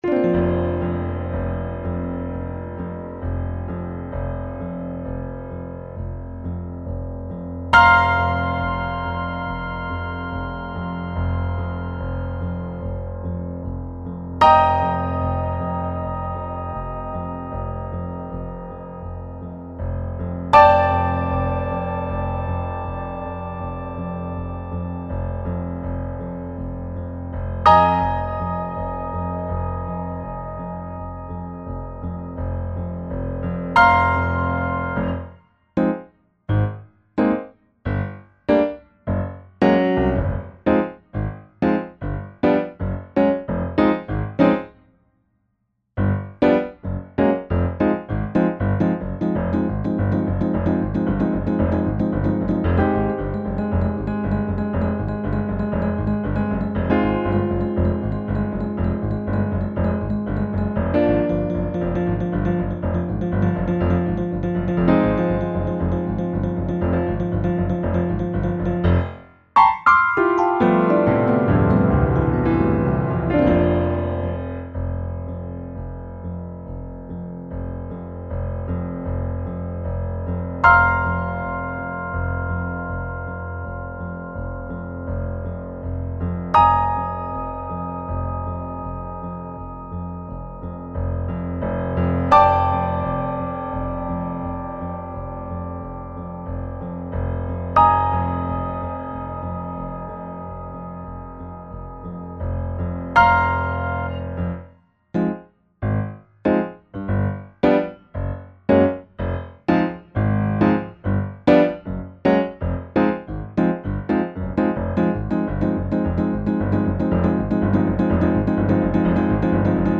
gdy pojawi się 1 górny dźwięk - wstaje ręka
porusza się,  gdy w muzyce mamy pauzę - robot się zatrzymuje.
podkład do zabawy ROBOTY